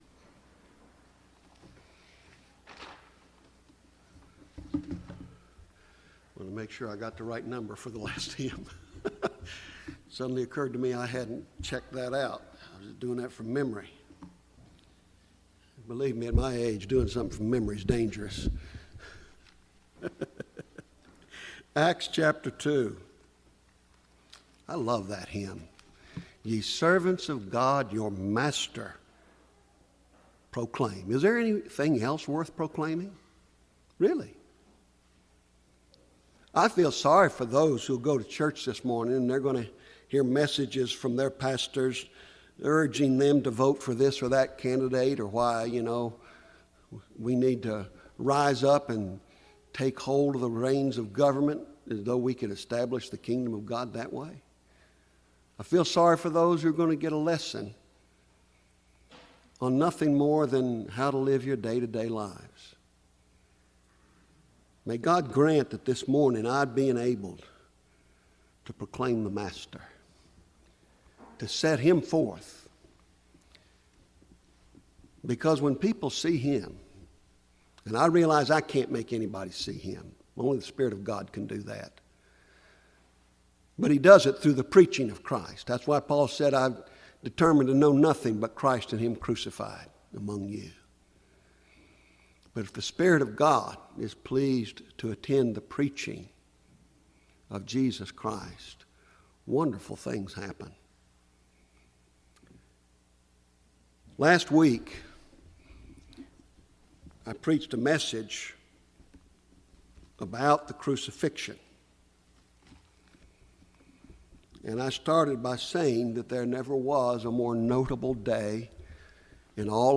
Full Blown Gospel | SermonAudio Broadcaster is Live View the Live Stream Share this sermon Disabled by adblocker Copy URL Copied!